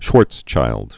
(shwôrtschīld, shvärtsshĭld)